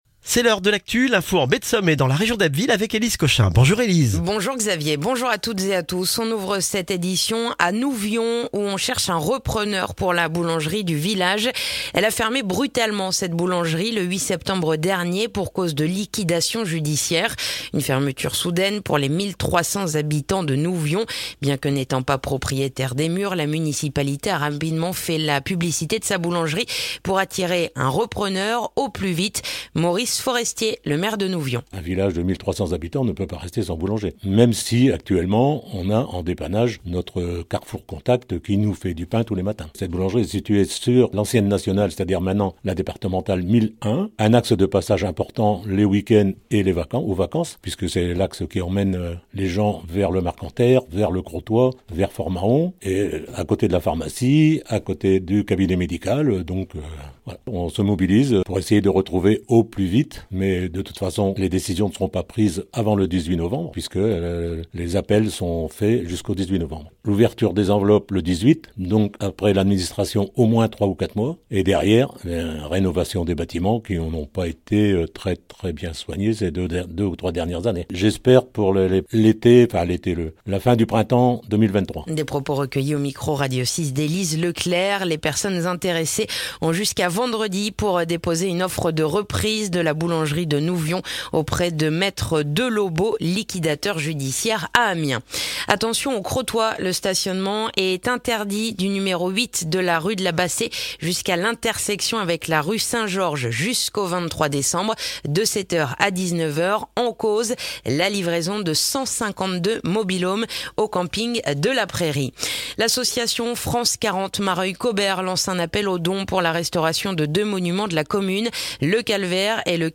Le journal du mardi 15 novembre en Baie de Somme et dans la région d'Abbeville